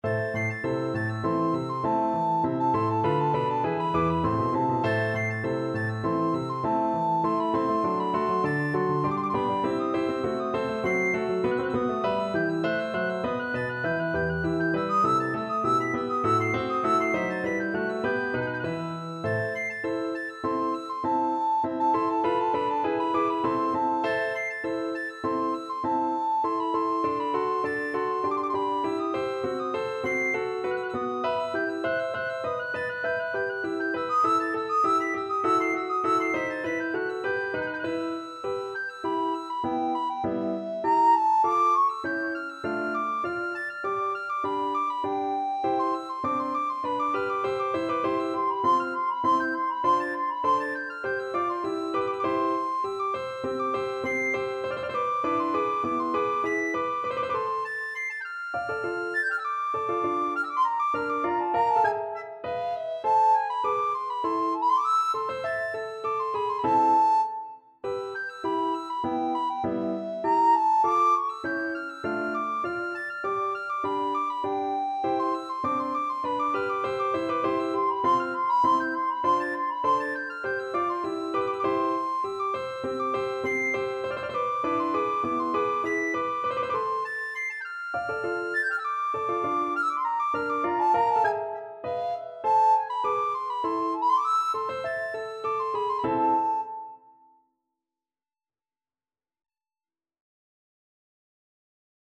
Free Sheet music for Soprano (Descant) Recorder
2/4 (View more 2/4 Music)
Classical (View more Classical Recorder Music)